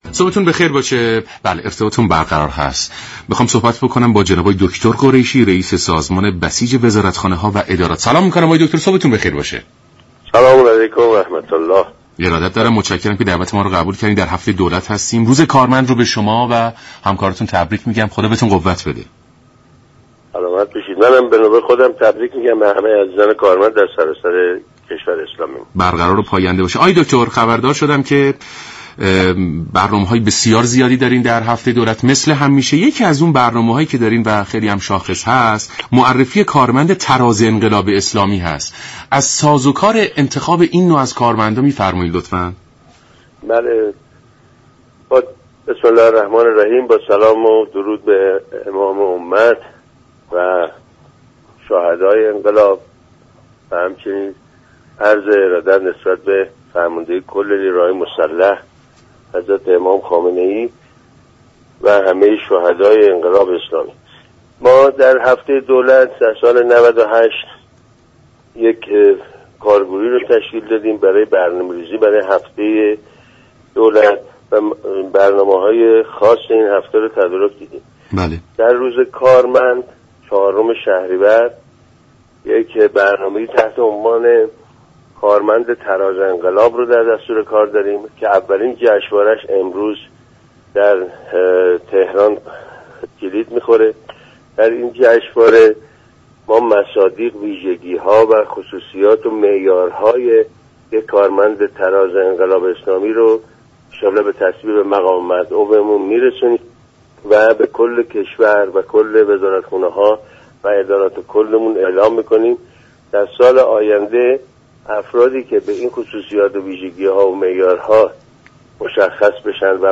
به گزارش شبكه رادیویی ایران حمیدرضا قریشی رییس سازمان بسیج وزارتخانه ها و ادارات در سومین روز از هفته دولت در گفت و گو با برنامه «سلام صبح بخیر» از برگزاری نخستین جشنواره كارمند تراز انقلاب اسلامی در روز كارمند خبر داد و گفت: در این جشنواره مصادیق و ویژگی های كارمند تراز انقلاب اسلامی شناسایی شده و به تمامی وزارتخانه ها و ادارات سراسر كشور اعلام شده است.